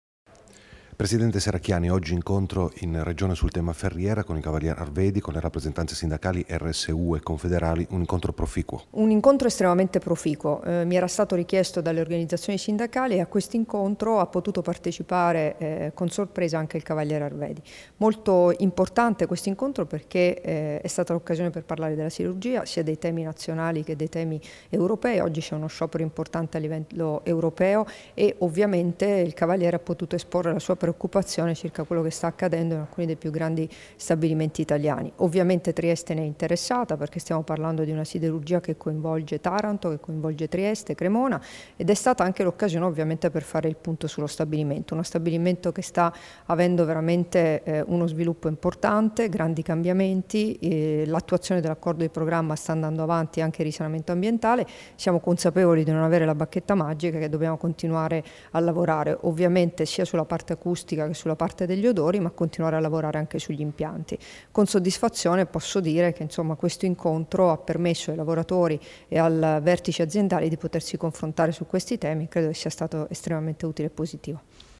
Dichiarazioni di Debora Serracchiani (Formato MP3) [1207KB]
a margine dell'incontro con rappresentanti sindacali dei lavoratori della Ferriera di Servola, rilasciate a Trieste il 9 novembre 2016